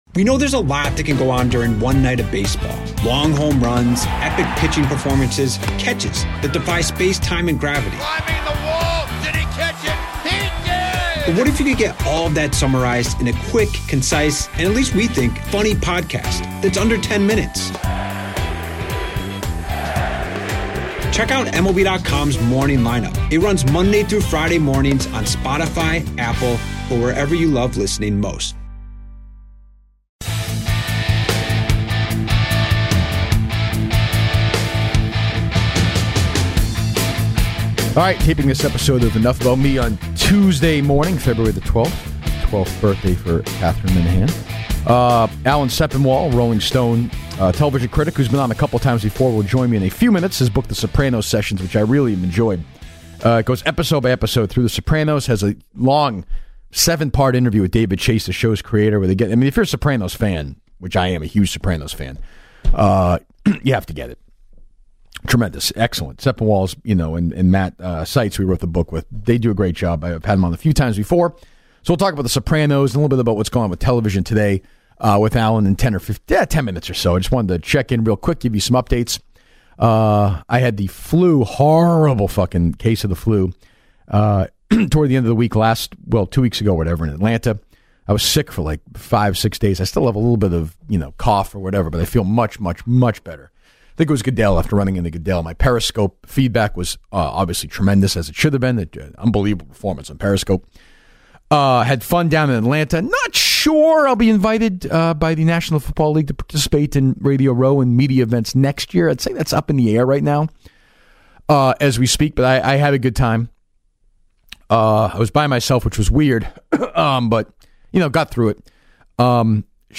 The two chat about their favorite episodes and how the show stacks up against today's shows.